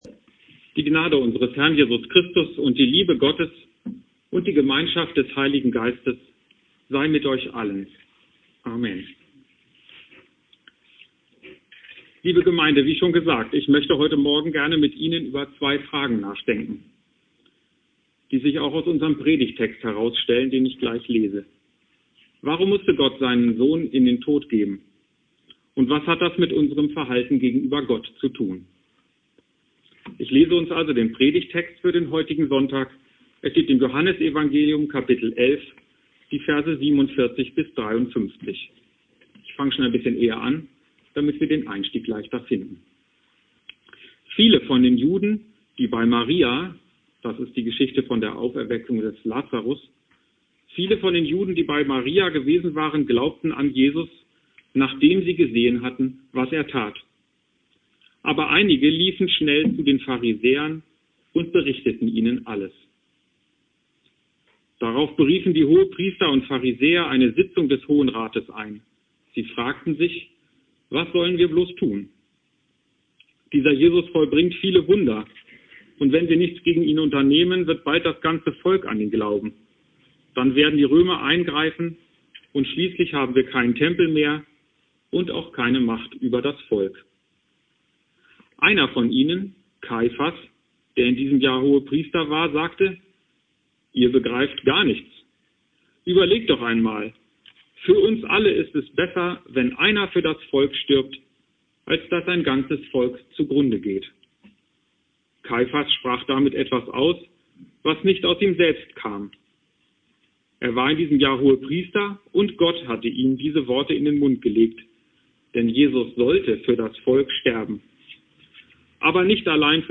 Predigt
Inhalt der Predigt: Predigt zum Abschluss der Prädikantenausbildung (Aufnahme aus dem Haus Jona) Bibeltext: Johannes 11,47-53 Dauer: 17:42 Abspielen: Ihr Browser unterstützt das Audio-Element nicht.